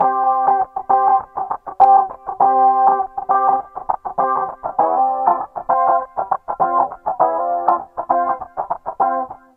Loops guitares rythmique- 100bpm 3
Guitare rythmique 48